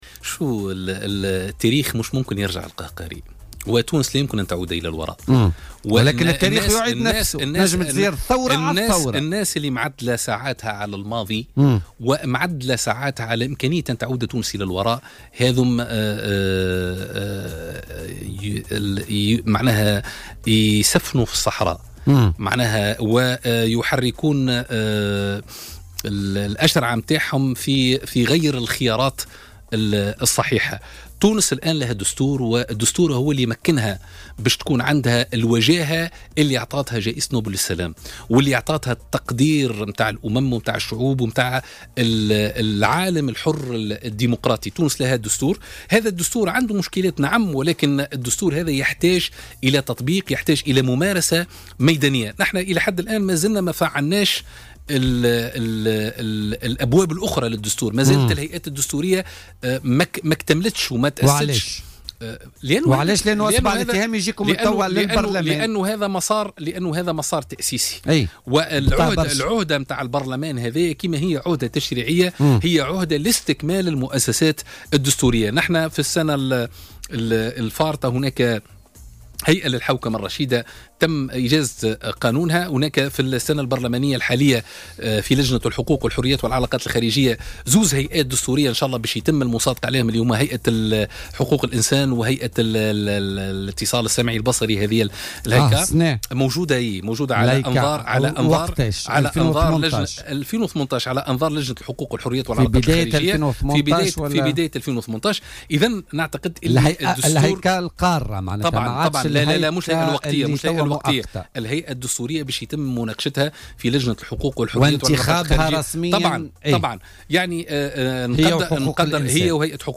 وأضاف ضيف "بوليتيكا" اليوم الخميس 14 ديسمبر 2017، أن المجلس يراهن على استكمال تركيز جميع المؤسسات الدستورية، وأبرزها المحكمة الدستورية.